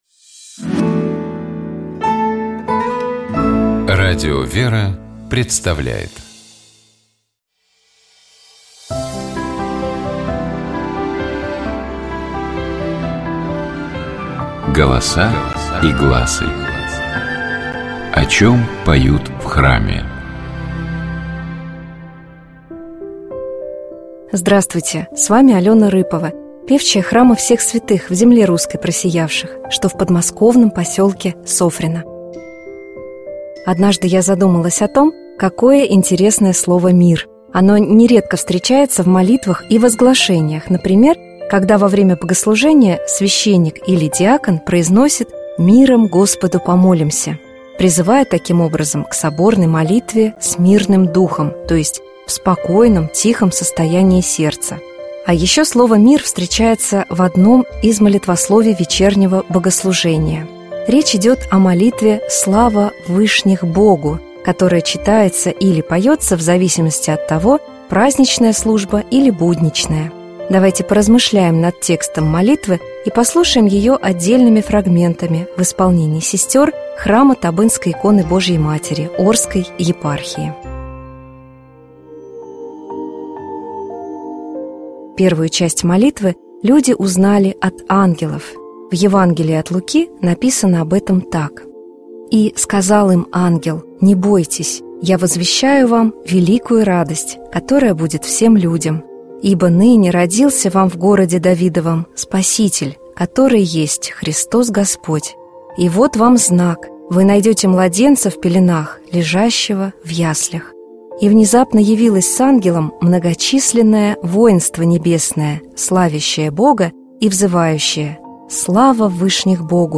Давайте поразмышляем над текстом молитвы и послушаем её отдельными фрагментами в исполнении сестёр храма Табынской иконы Божией Матери Орской епархии.
Эта часть исполняется троекратно.
Этот отрывок поётся дважды.
Давайте послушаем песнопение «Слава в вышних Богу» полностью в исполнении сестёр храма Табынской иконы Божией Матери Орской епархии.